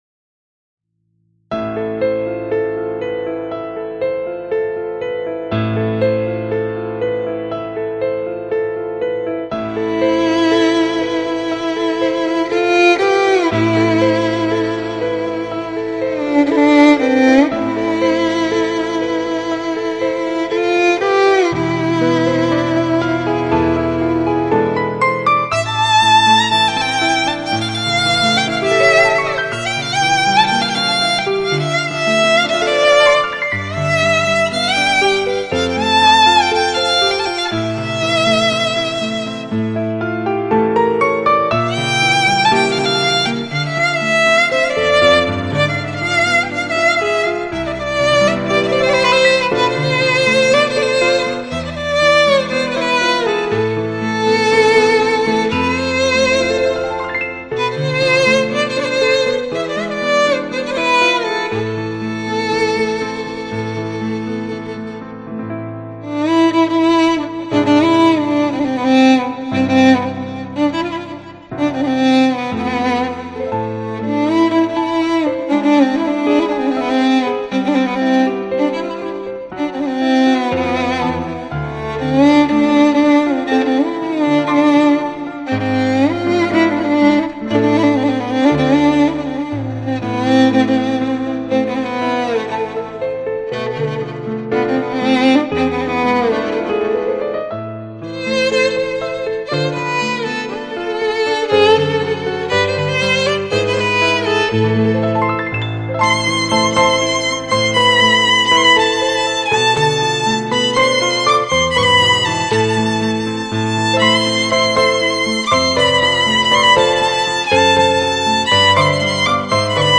این قطعه بیکلام با ساز ویولن را از دست ندهید
آخرین خبر/ قطعه بیکلام و آرامش بخش با ساز ویولن تقدیم به شما.